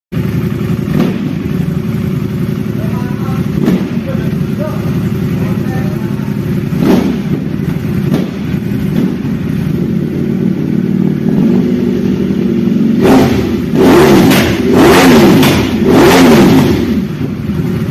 Eargasm Ducati Panigale V4S Sound Effects Free Download